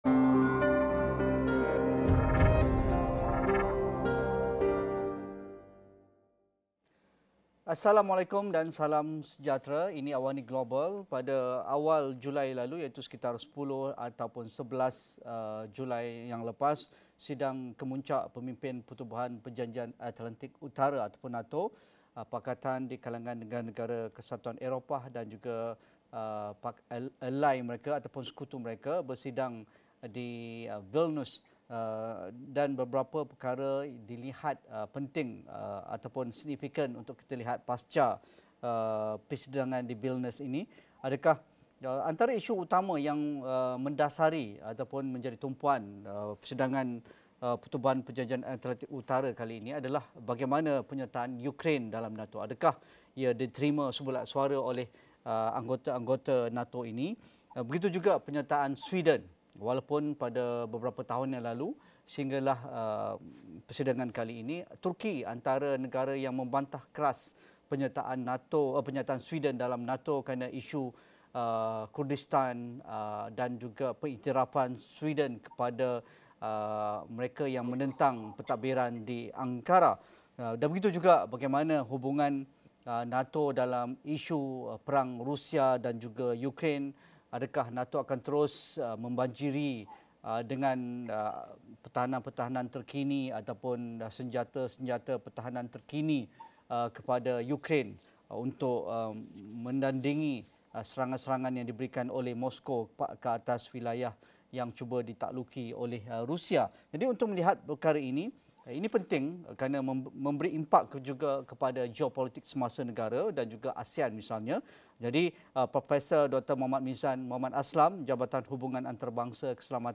Diskusi dan analisis perluasan Pakatan Euro-Atlantik dan pasca sidang NATO di Vilnius